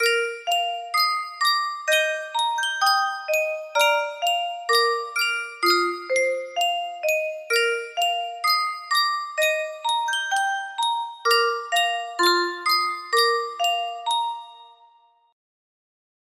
Sankyo Custom Tune Music Box - Nearer My God to Thee
Full range 60